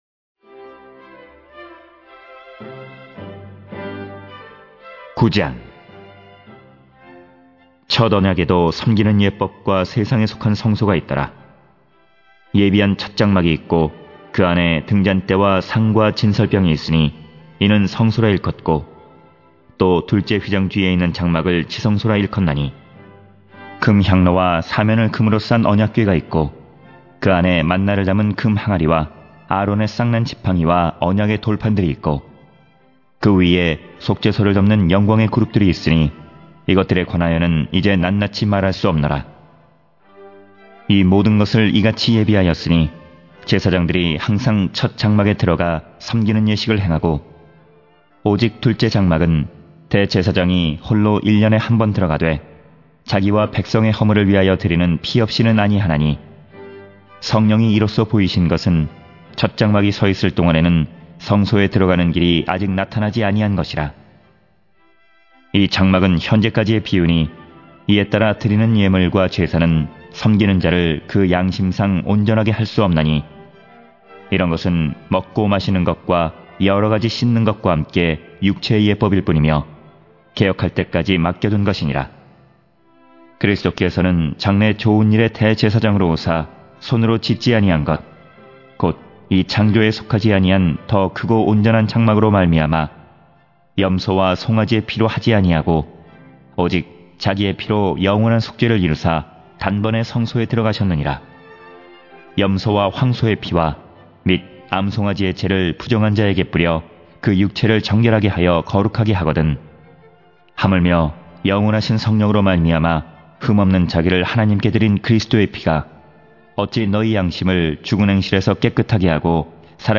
성경 듣기(개역개정) + 성경 읽기(쉬운성경)